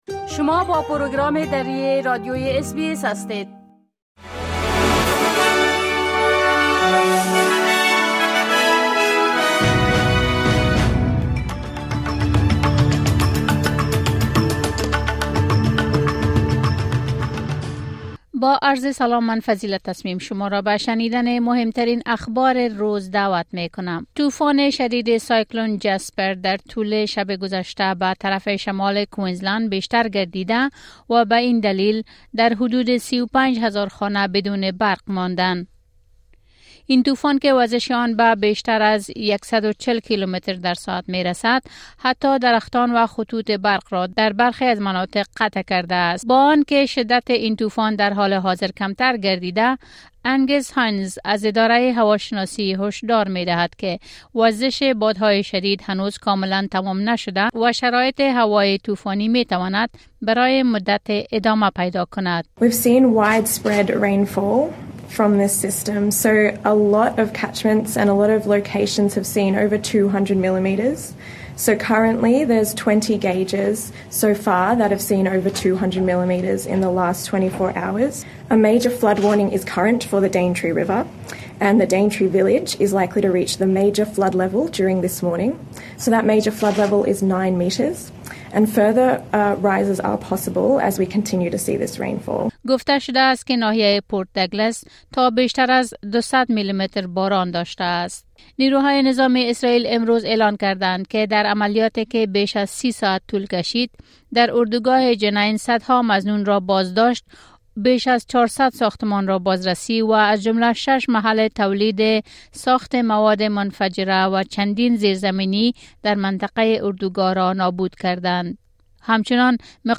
خلاصۀ اخبار روز از اس‌بى‌اس دری